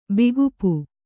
♪ bigupu